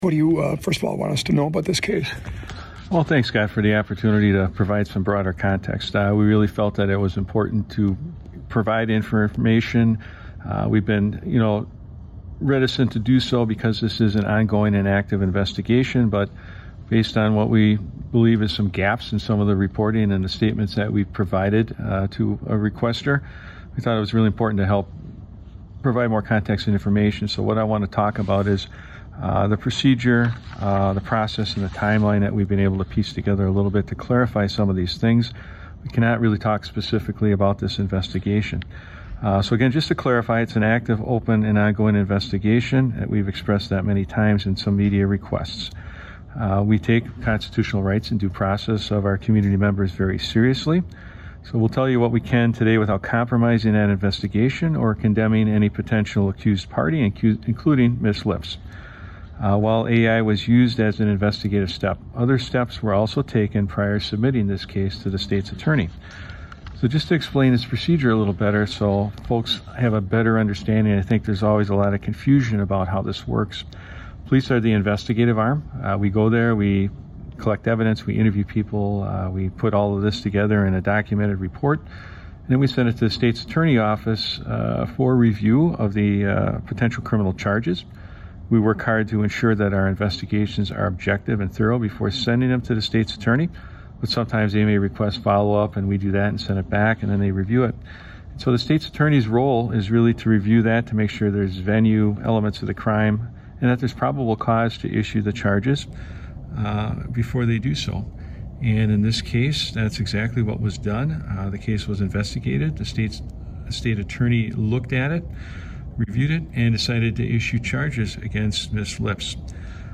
exclusive interview